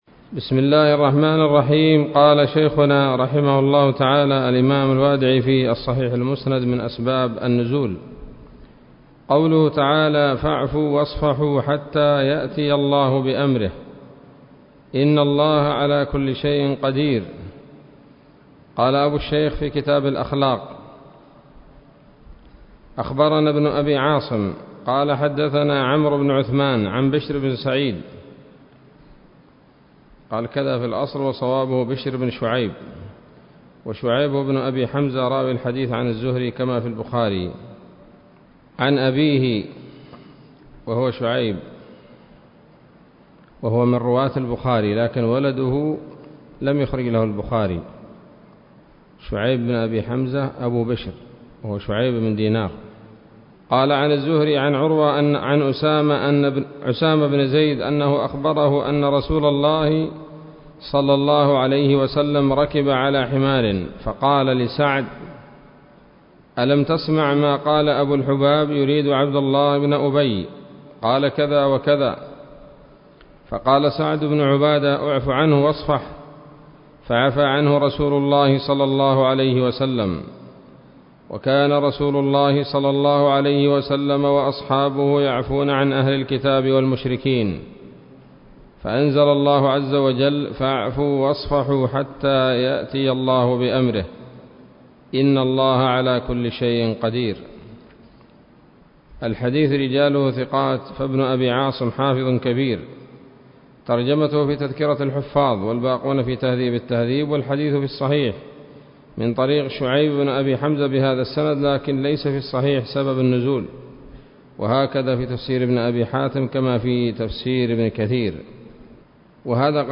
الدرس السابع من الصحيح المسند من أسباب النزول